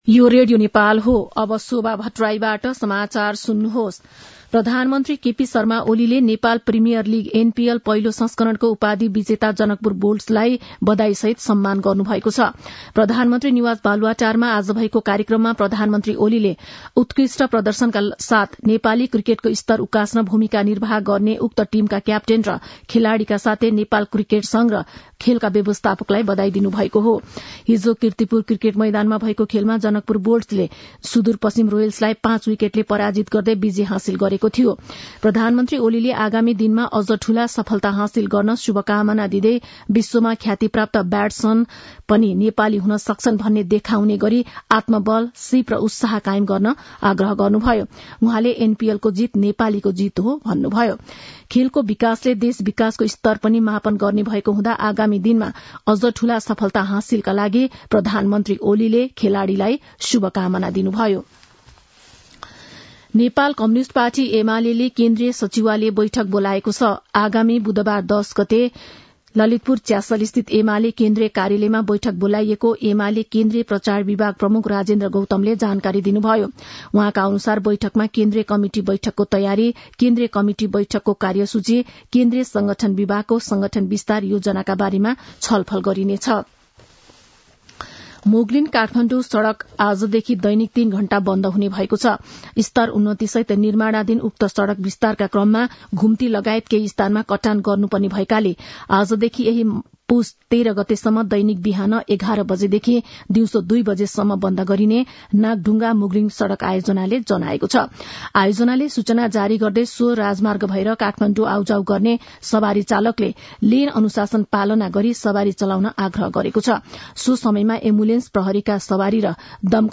मध्यान्ह १२ बजेको नेपाली समाचार : ८ पुष , २०८१
12-am-nepali-news-1-16.mp3